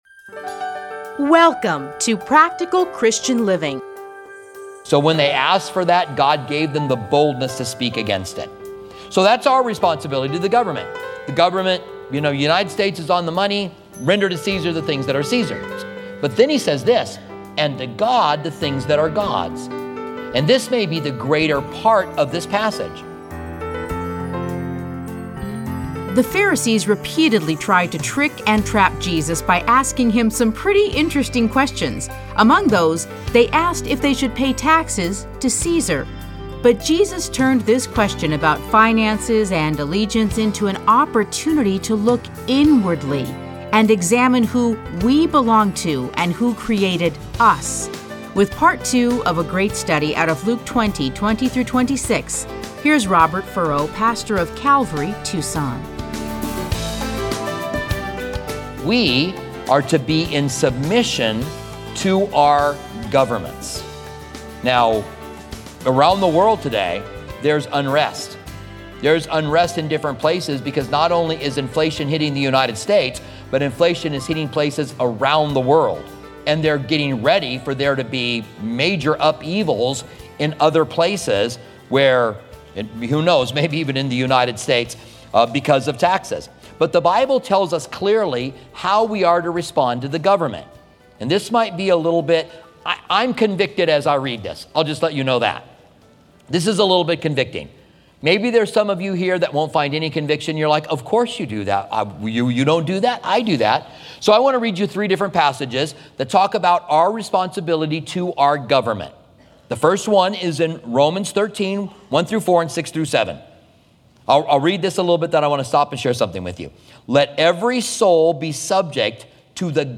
Listen to a teaching from Luke 20:20-26.